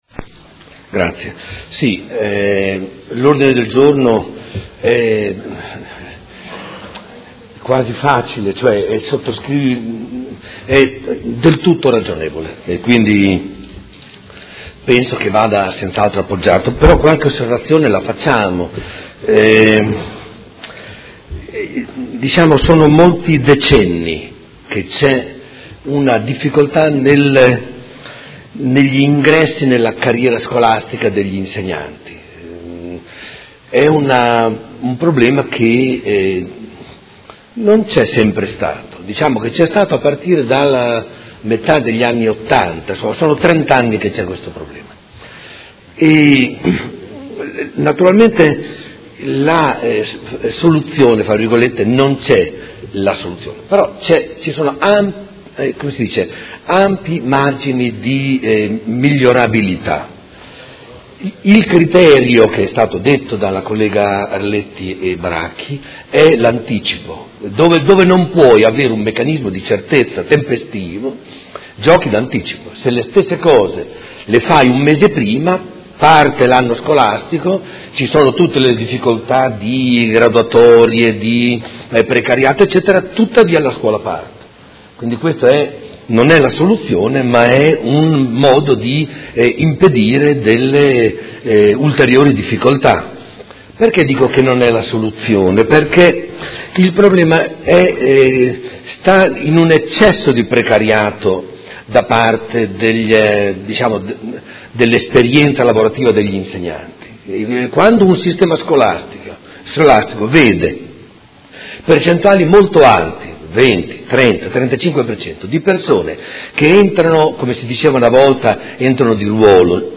Seduta del 11/10/2018. Dibattito su Ordine del Giorno presentato dai Consiglieri Baracchi, Arletti, Di Padova, Forghieri, Poggi, Lenzini e Liotti (PD) avente per oggetto: Disagi avvio anno scolastico, ed emendamento